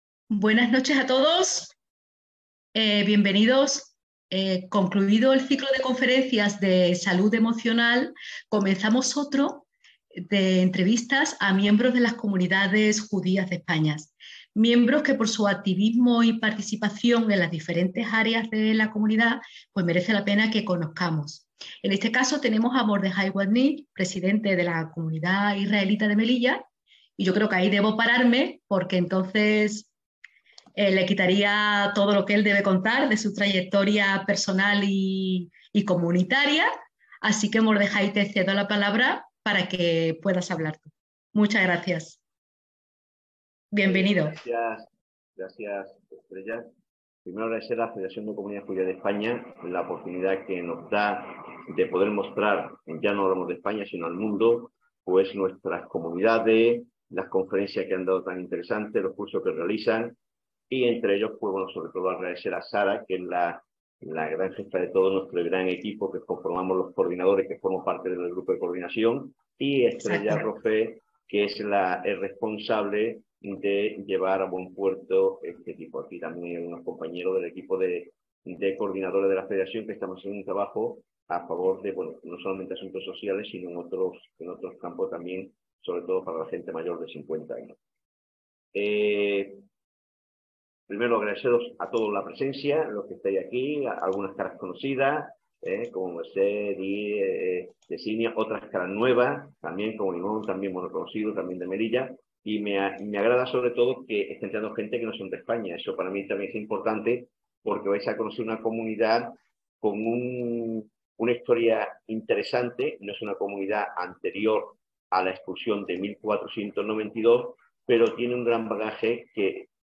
Charla sobre la Comunidad Israelita de Melilla (online, 23/11/2022)